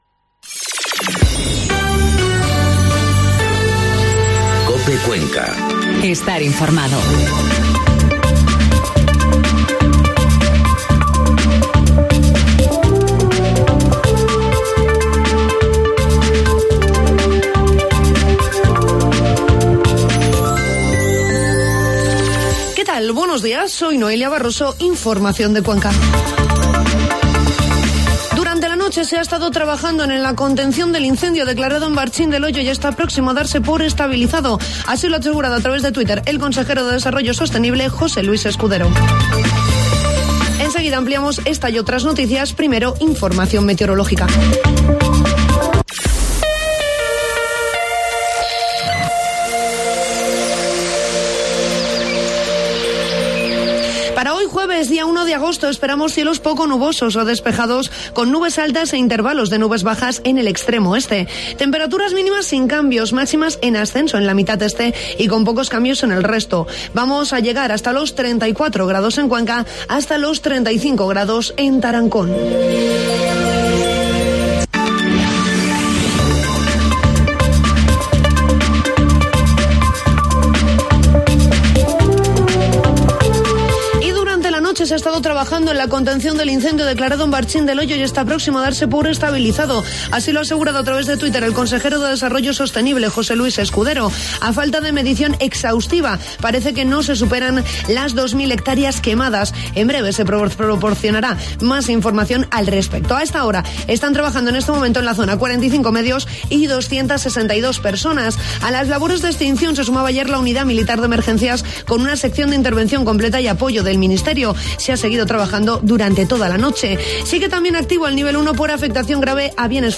Informativo matinal COPE Cuenca 1 de agosto